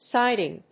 /sάɪdɪŋ(米国英語)/